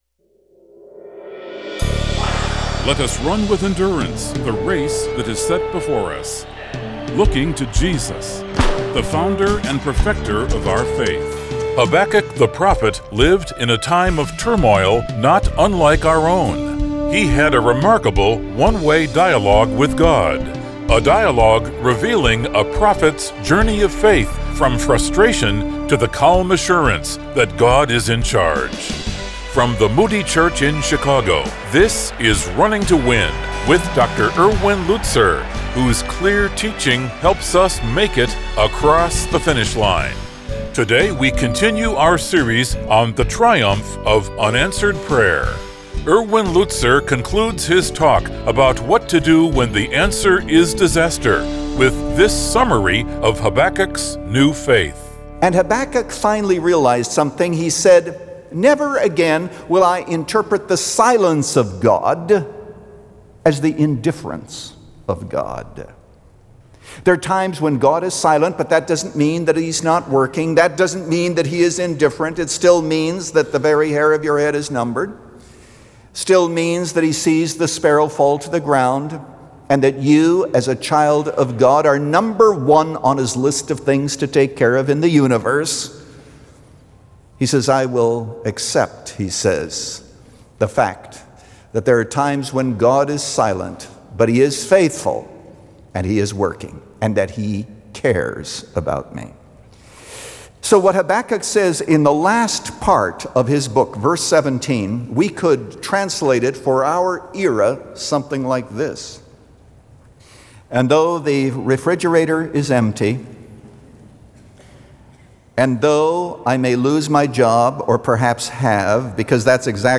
Since 1998, this 15-minute program has provided a Godward focus.